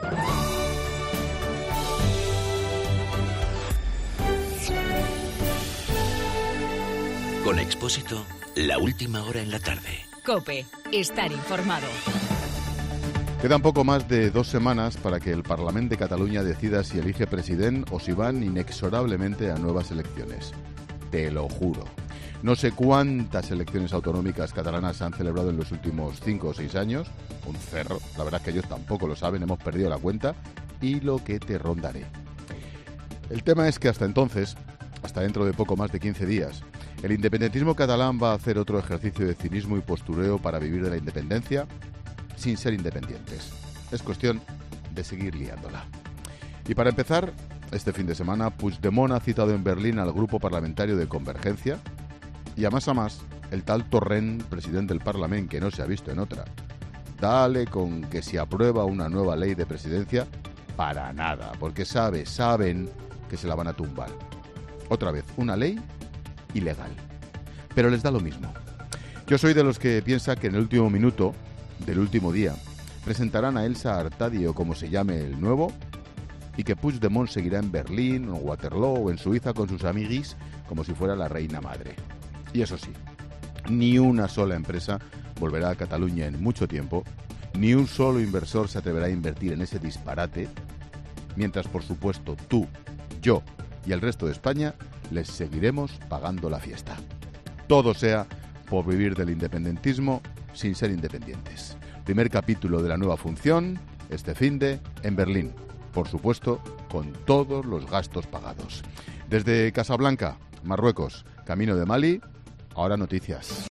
Monólogo de Expósito
El comentario de Ángel Expósito de las 17h desde Casablanca (Marruecos) antes de volar a Bamako (Mali).